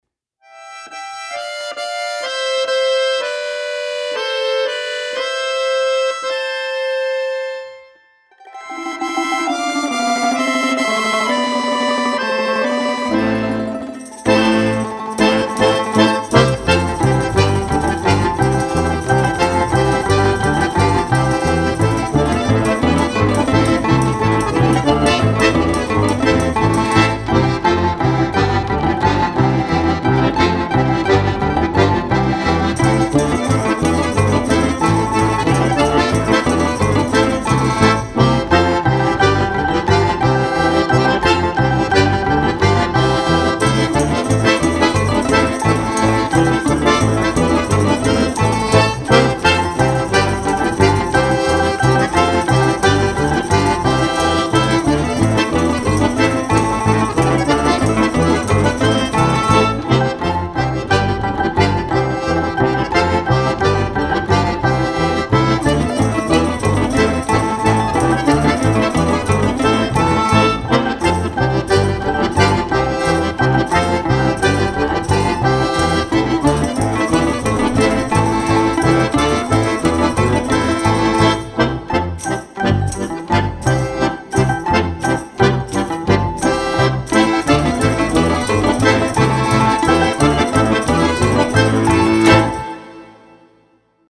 Запись / сведение ансамблей